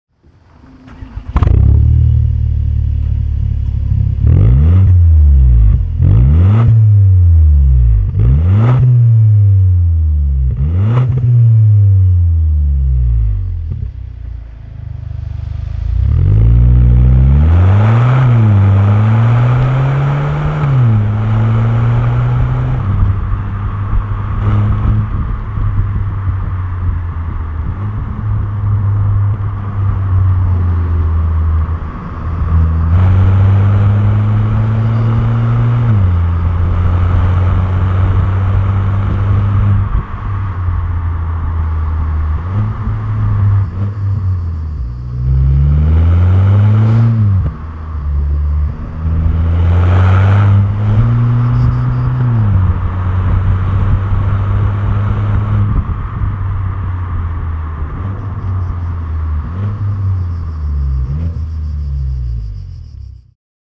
Ragazzon Evo Line Mittelrohr Gruppe N in Edelstahl
Die Ragazzon Produkte zeichnen sich durch hervorragende Verarbeitung, tollen, kräftig-sportlichen Sound sowie ein überzeugendes Preis-Leistungsverhältnis aus.